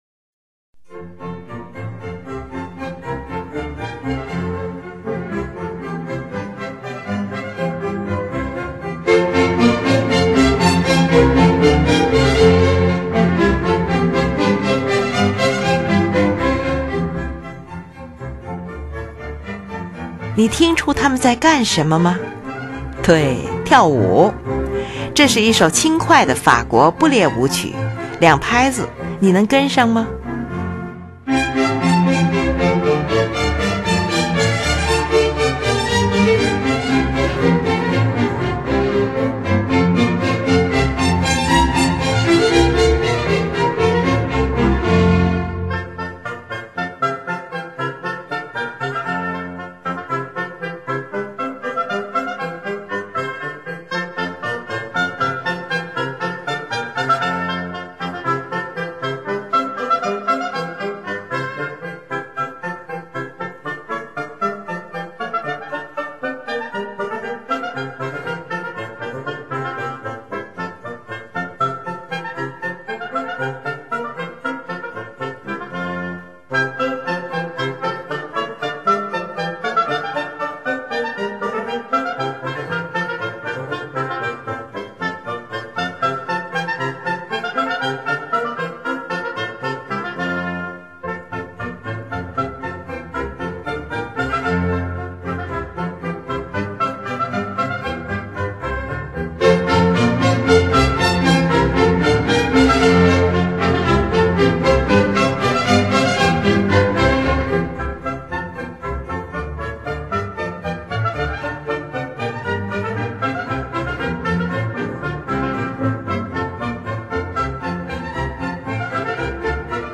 是一部管弦乐组曲。
乐器使用了小提琴、低音提琴、日耳曼横笛、法兰西横笛、双簧管、圆号、小号等